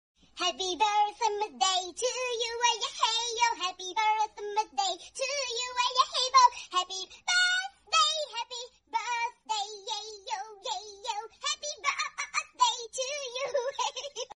Happy Birthday to You funny sound effects free download